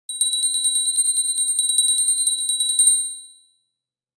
Gentle Little Bell Ringing Sound Effect
This audio captures a gentle, tiny bell ringing with a soft and cute tone. The sound of a short, cute little bell ring call adds a festive Christmas and holiday mood.
Gentle-little-bell-ringing-sound-effect.mp3